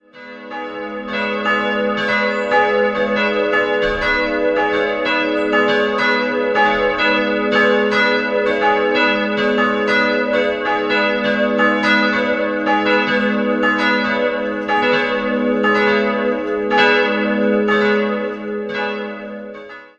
Im Stil des Klassizismus sind die Kanzel und der Taufstein von 1793. 3-stimmiges TeDeum-Geläute: gis'-h'-cis'' Die beiden größeren Glocken wurden 1950 von Junker in Brilon, die kleine 1723 von Mathias Stapf in Eichstätt gegossen.